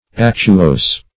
Actuose \Ac"tu*ose`\